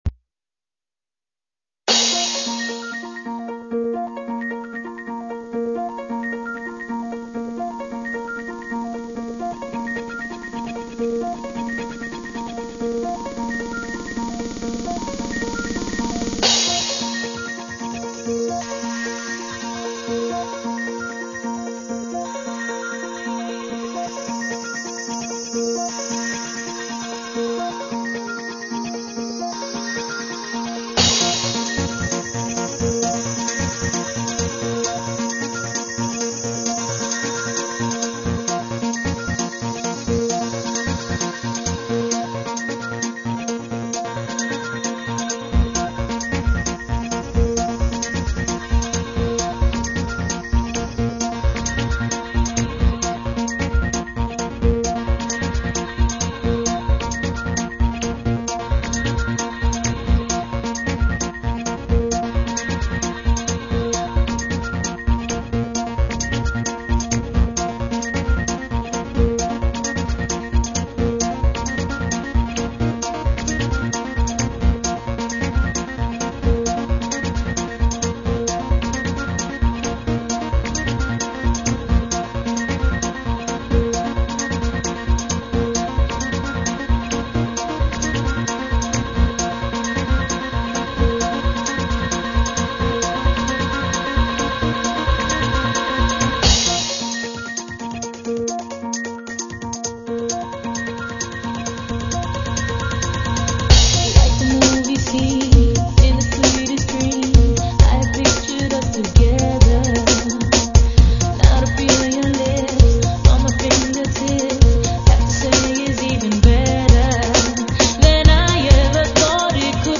new remix of the dance classic